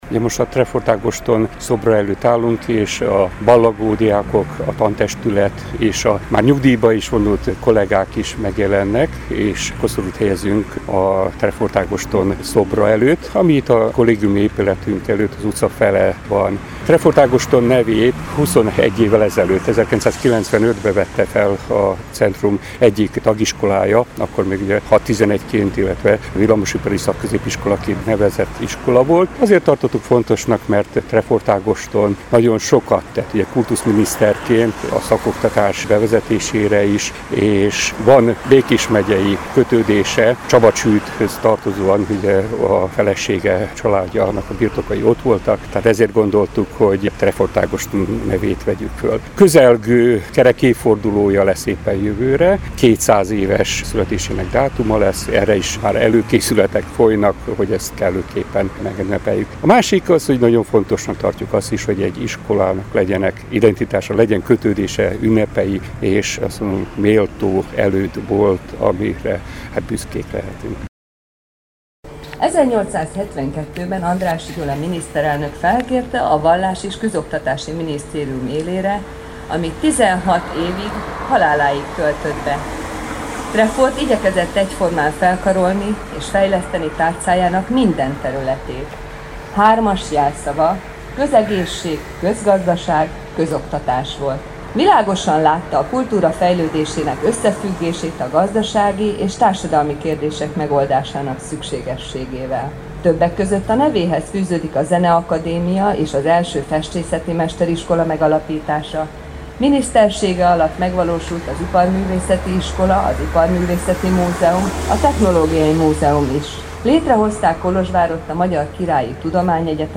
A koszorúzás alkalmából megemlékező gondolatok hangzottak el Trefort Ágoston életútjával kapcsolatban, valamint méltatták a névadó munkássága és az iskola közötti kapcsolódásokat.